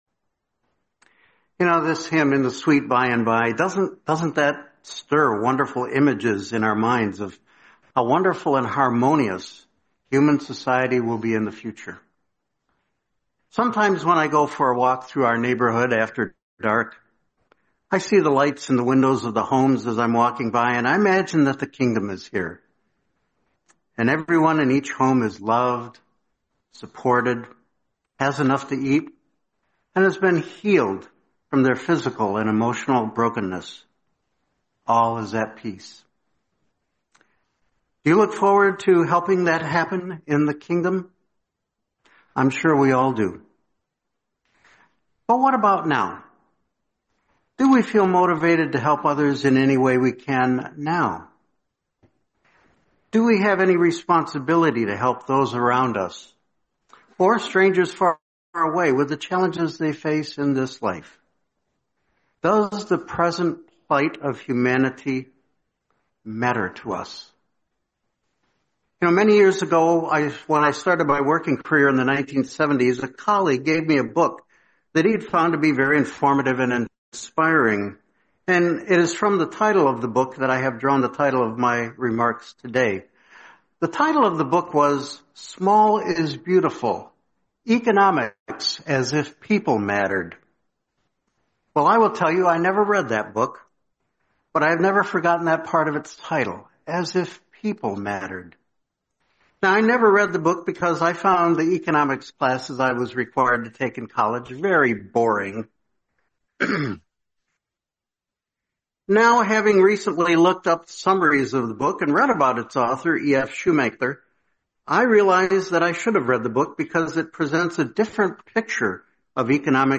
Series: 2025 Florida Convention